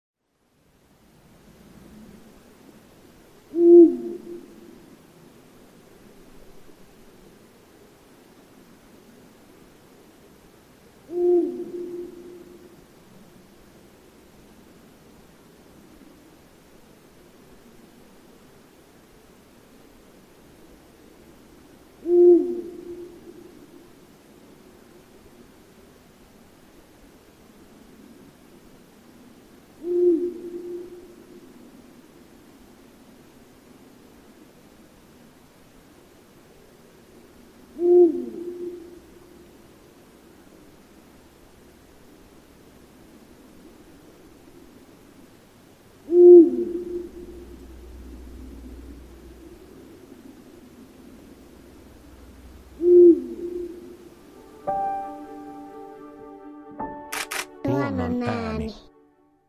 Kuuntele: Huuhkajan soidinääni on matala ja kumea – tunnistatko äänen?
Huuhkajan soidinääni matala, kumea kaksitavuinen ”huu-uu, huu-uu…”, joka toistuu 7–10 sekunnin välein. Ensimmäinen tavu on painokkaampi. Naaraan ääni on korkeampi. Huuhkajan tavallinen yhteysääni on käheä ”rräh”, mutta lajilla on monenlaisia muitakin rääkyviä, parkuvia ja haukahtavia ääniä.